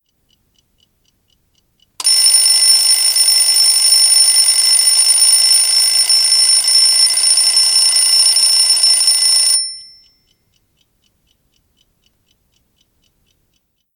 Index of /informatyka/8_Tworzymy/Swiat dzwiekow i obrazow/Budzik/
Alarmclock-mechanical.mp3